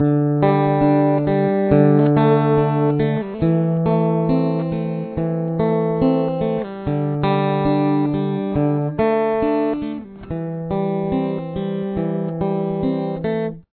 Guitar 2
This part is played when the fifth measure comes in above.